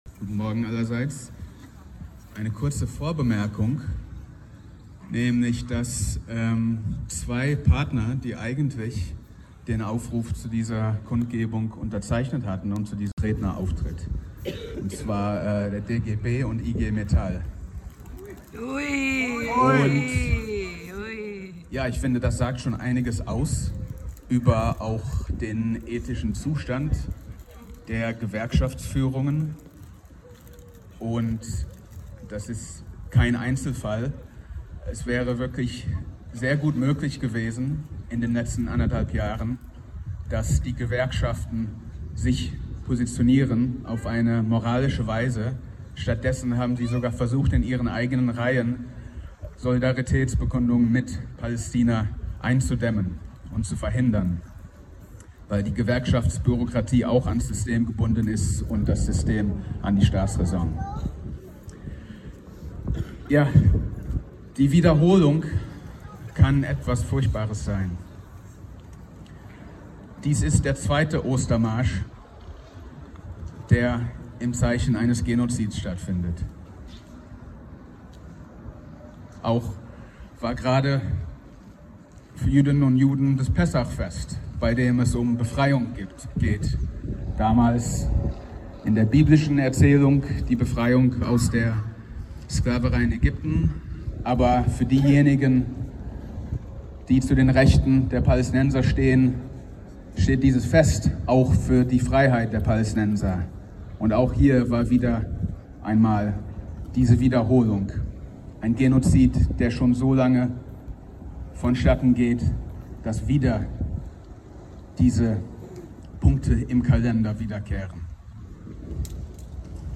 Unsere diesjährige Kundgebung fand bei passablem Wetter statt.
Gegen Ende waren wir 100 Personen.
Es gab viel Applaus.
Rede-Ostermarsch-2025.mp3